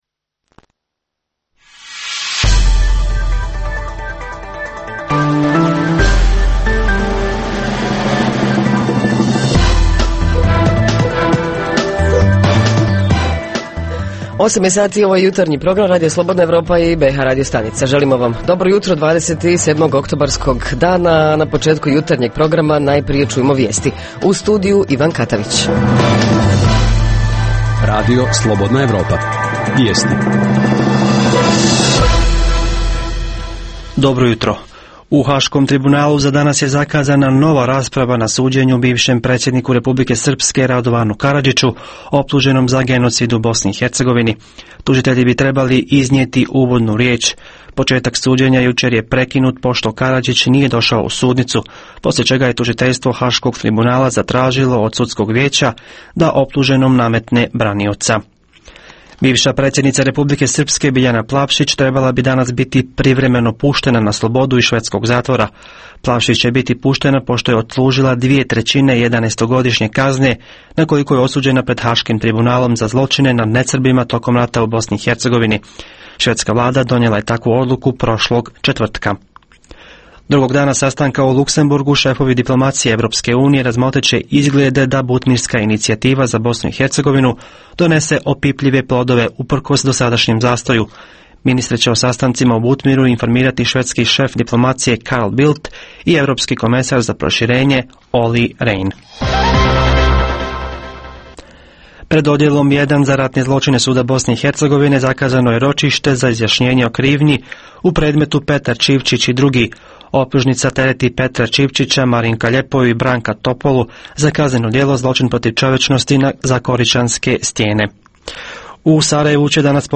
Redovna rubrika Radija 27 utorkom je "Svijet interneta". Redovni sadržaji jutarnjeg programa za BiH su i vijesti i muzika.